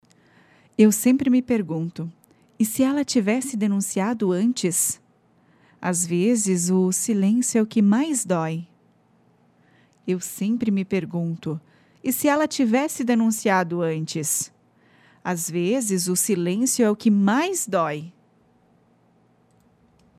A Apresentadora (V2): Deve transmitir segurança, sobriedade e acolhimento.
A voz precisa ser firme ao dar os números (190 e 180), mas manter a suavidade para não assustar a vítima que pode estar ouvindo.